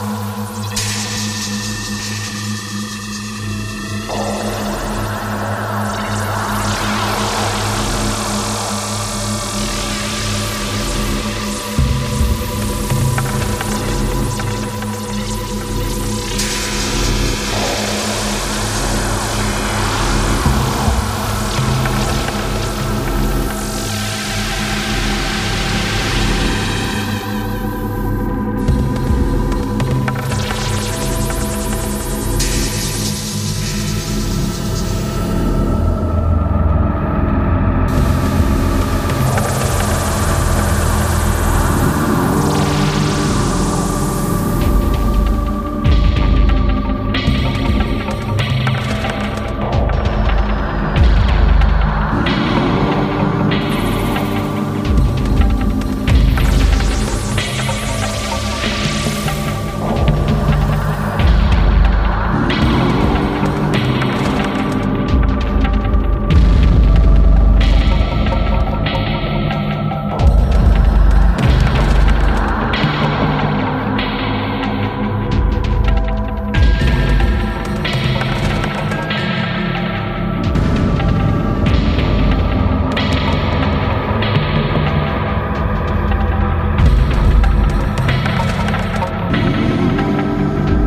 IDM/Electronica